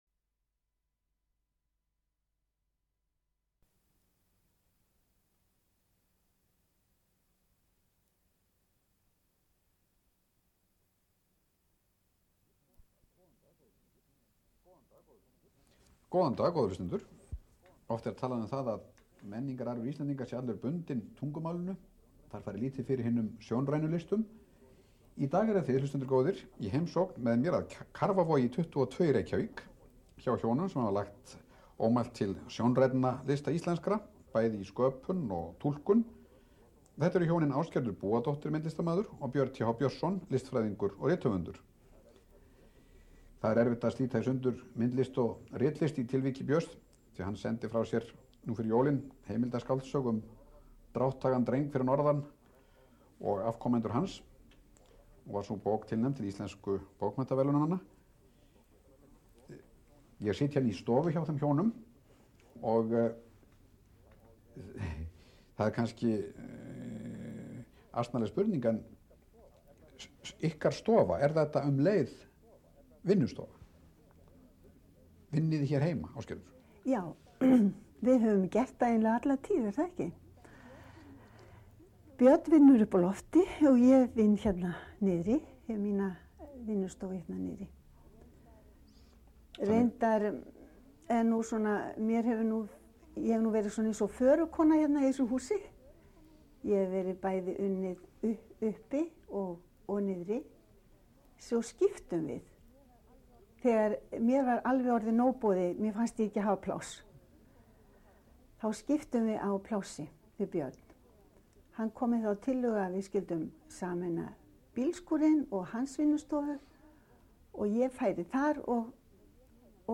Viðtöl Opna skjalið Ásgerður Búadóttir: sem vefnum gegnir UMF0796 Listasafn Íslands, 1994, 63 blaðsíður Viðtöl Ásgerður Búadóttir – Vinnustofuspjall UMF0744 RÚV, 12. júni 1994, 25 mín.